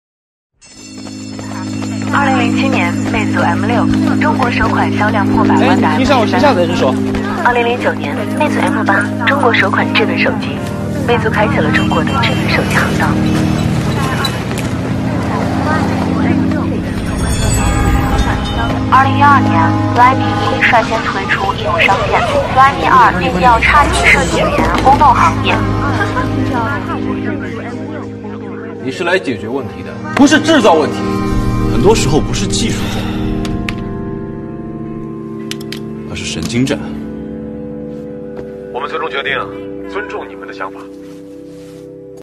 男C10-魅族TVC
男C10-质感大气 大气浑厚
男C10-魅族TVC.mp3